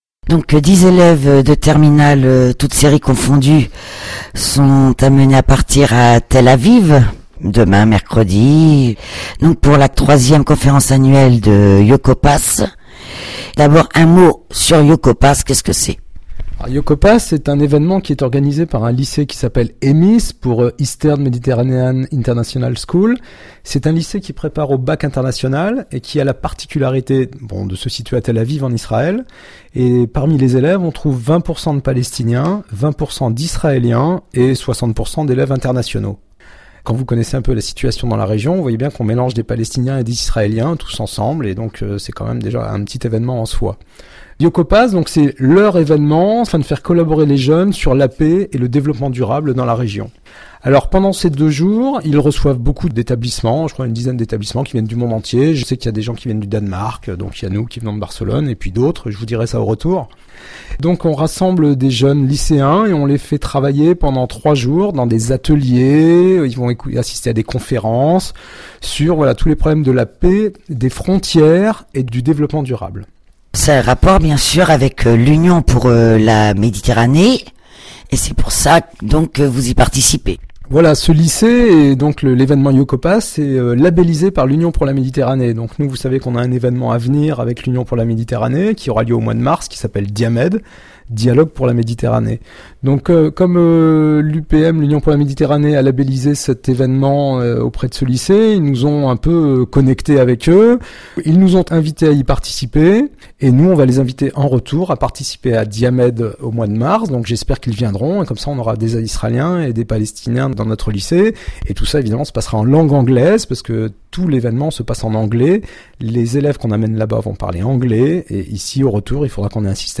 24/10/2017 à 17h 00| 3 mn | interview| lycée | événement |télécharger